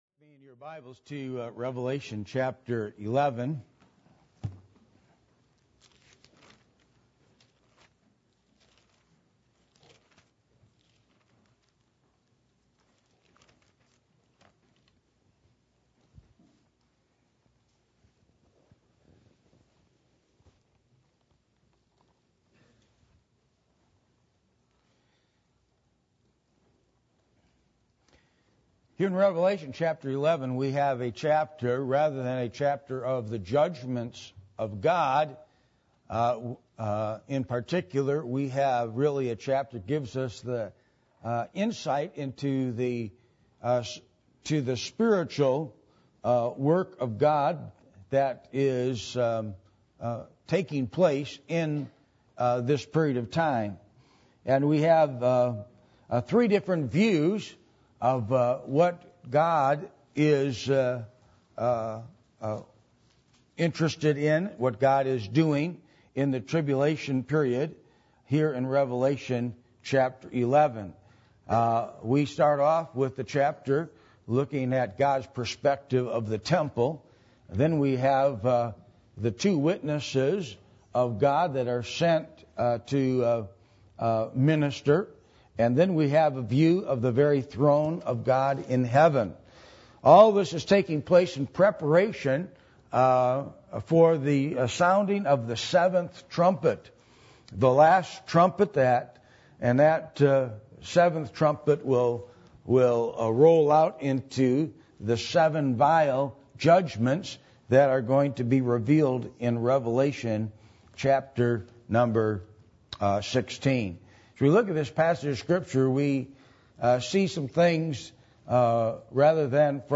Passage: Revelation 11:1-19 Service Type: Sunday Morning